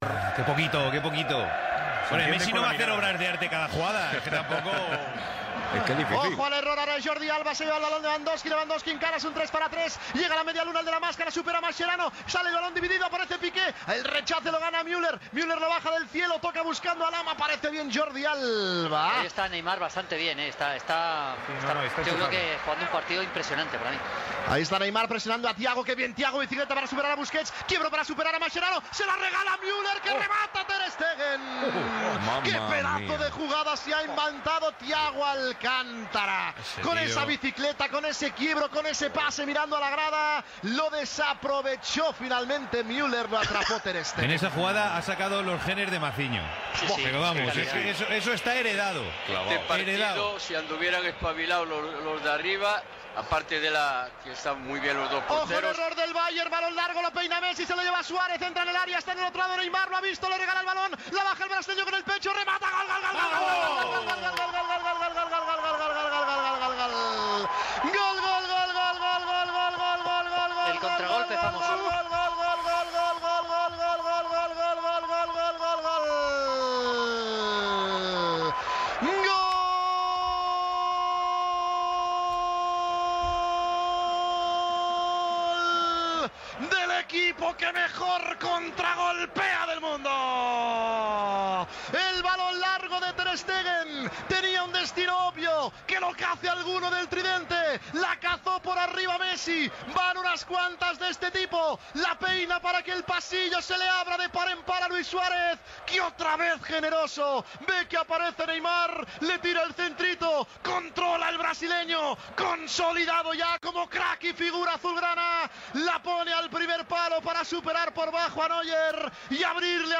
Transmissió del partit de tornada de la fase eliminatòria de la Copa d'Europa de futbol masculí entre el Bayern München i el Futbol Club Barcelona.
Narració d'una jugada del Bayer i del segon gol del Futbol Club Barcelona, marcat per Neymar. Reconstrucció de la jugada.
Esportiu